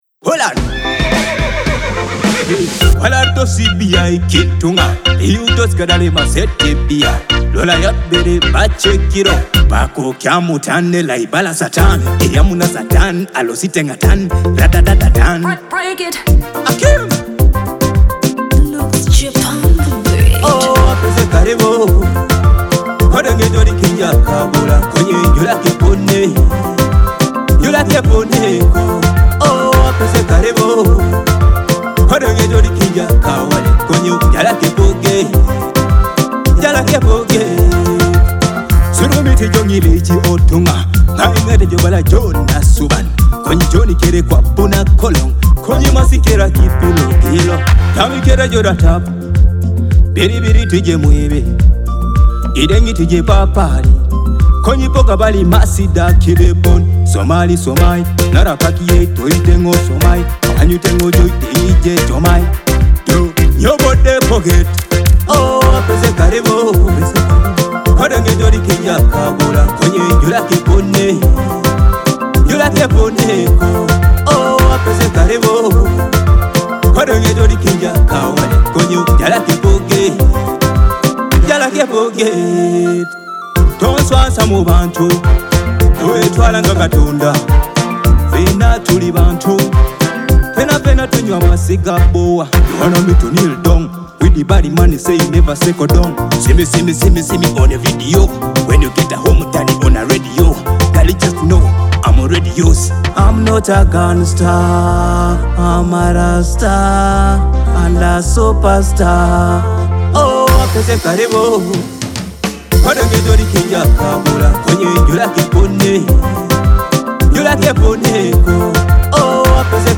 With energetic dancehall rhythms and dynamic vocals